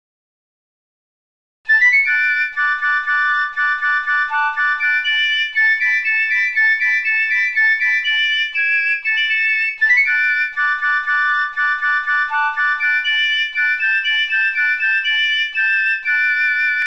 Basler Märsch
(numme d Aafäng)